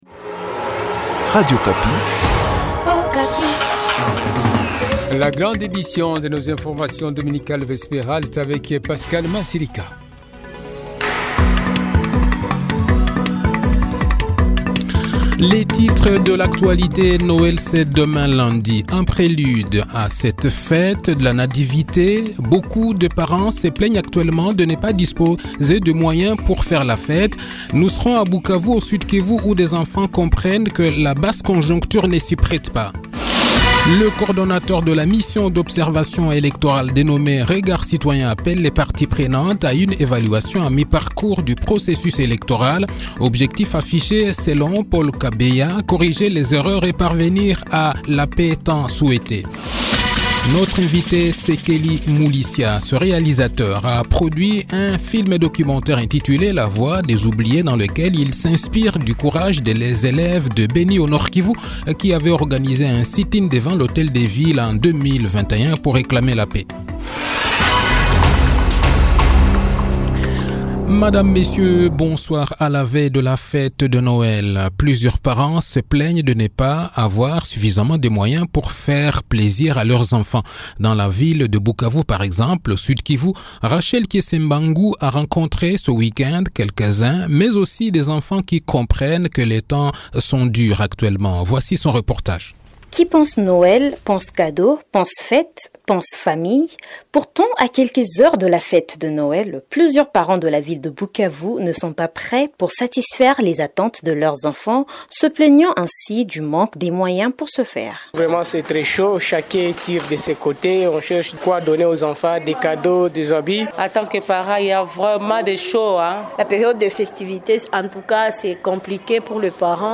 Le journal de 18 h, 24 decembre 2023